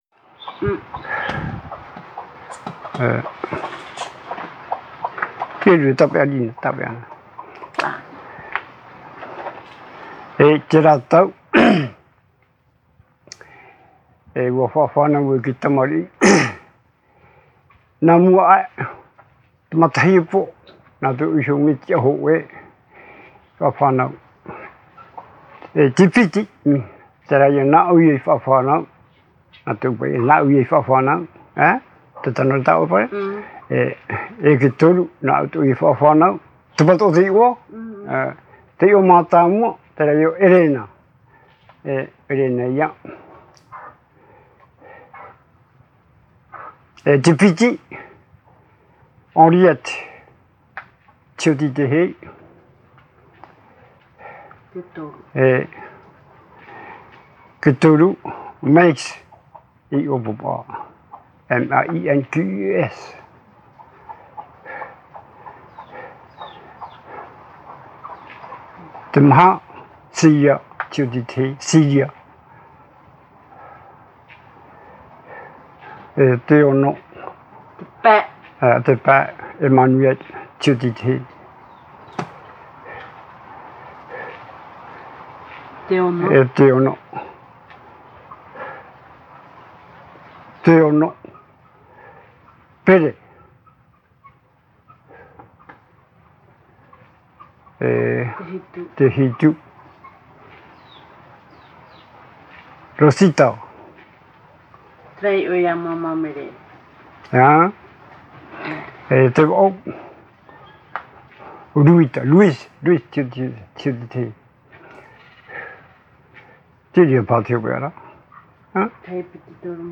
Récit